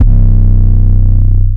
808 (19) Sacii.wav